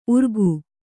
♪ urgu